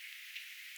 yksittäinen tsak-ääni vähän myöhemmin
yksittainen_tsak-aani_myoskin.mp3